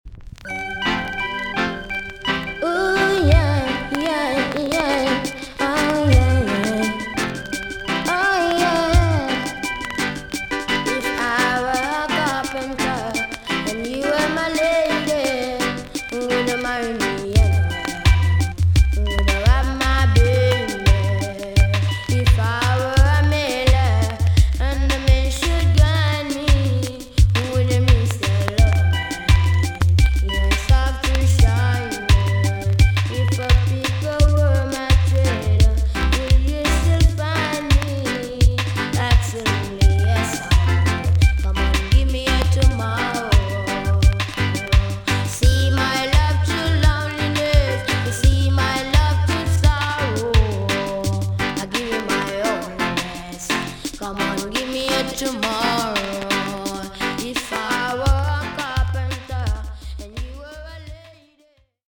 TOP >80'S 90'S DANCEHALL
EX-~VG+ 少し軽いチリノイズがありますが良好です。